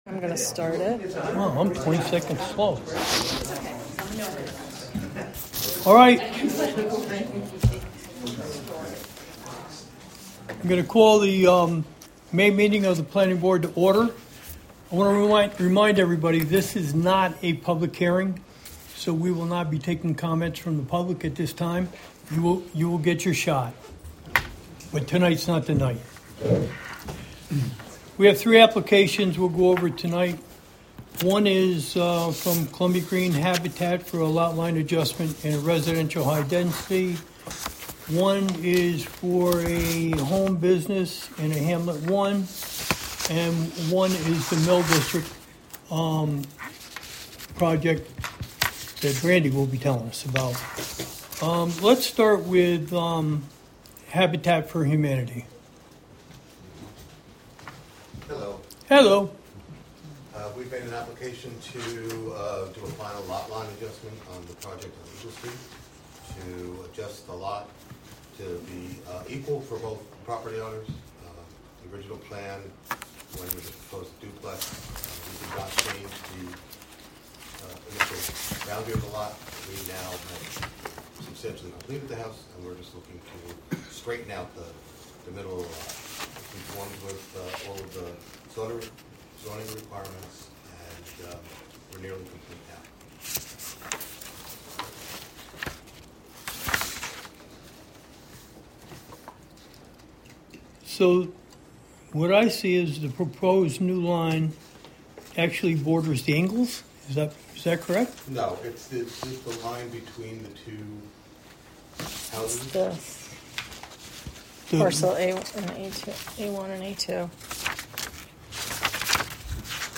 Live from the Village of Philmont: Philmont Planning Board Meeting 5-21-24 (Audio)